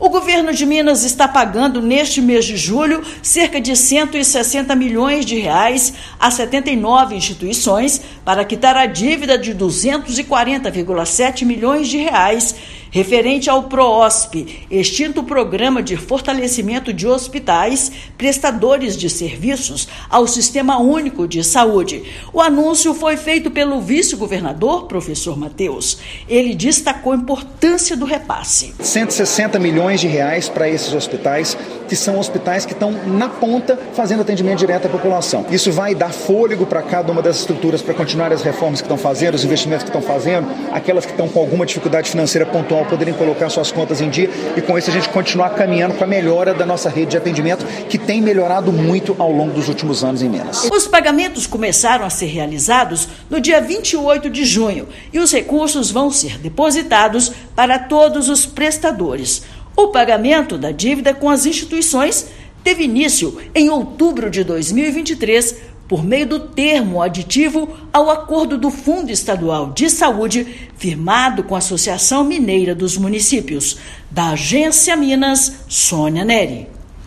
Total de 79 instituições serão pagas com R$ 160 milhões de recursos do Fundo Estadual de Saúde; antecipação de compromisso é recebida com alívio. Ouça matéria de rádio.